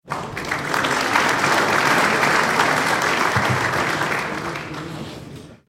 دانلود آهنگ تشویق تماشاگران از افکت صوتی طبیعت و محیط
دانلود صدای تشویق تماشاگران از ساعد نیوز با لینک مستقیم و کیفیت بالا
جلوه های صوتی